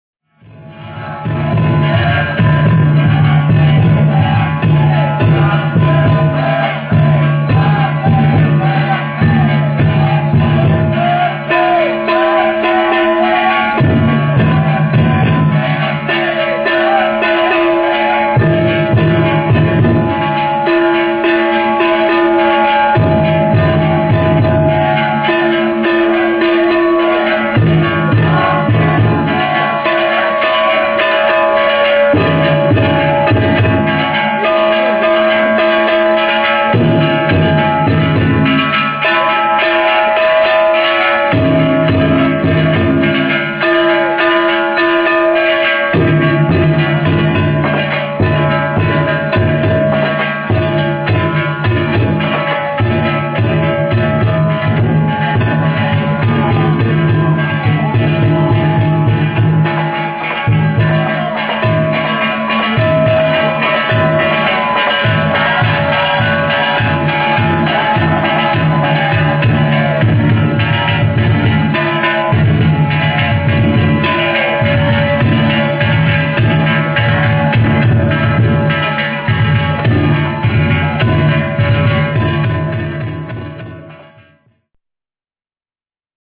平成２９年５月２７日、東灘区の御影クラッセだんじり広場3基集結を見に行ってきました。
感じのいいお囃子を囃しながらこちらへ向かってきます。